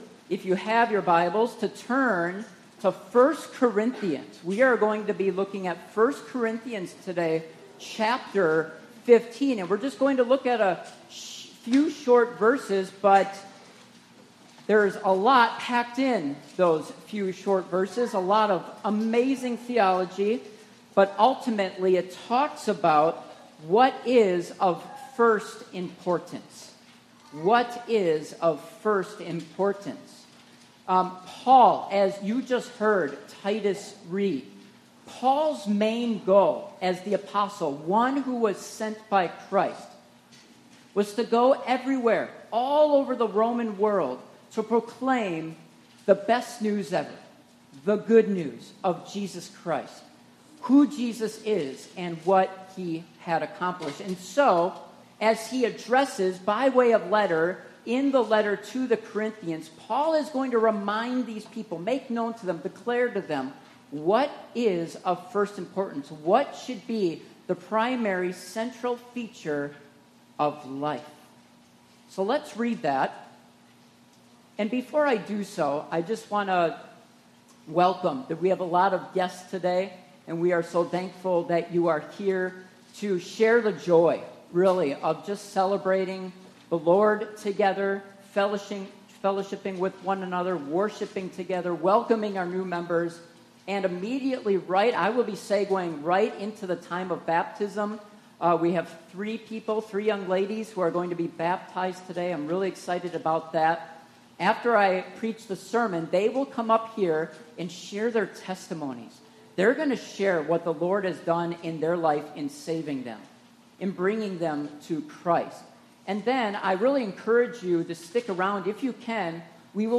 1 Corinthians 15:1-4 Service Type: Morning Worship Topics